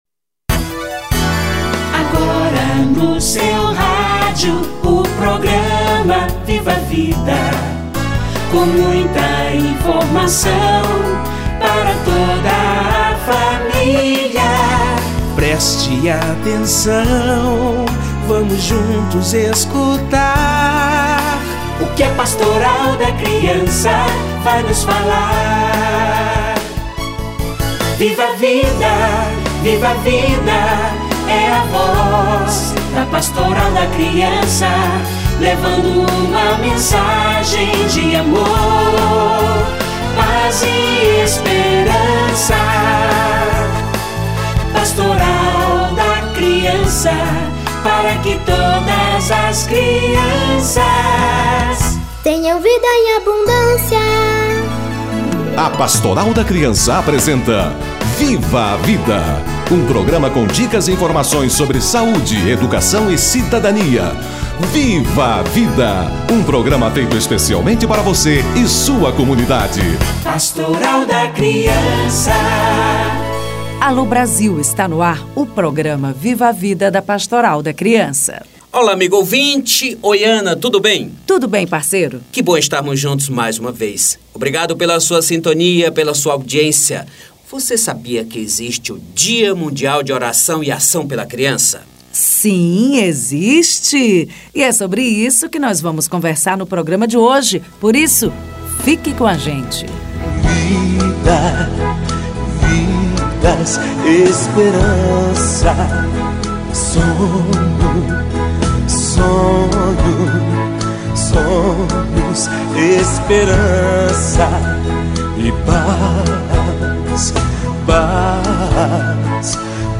Oração e Ação pela Criança - Entrevista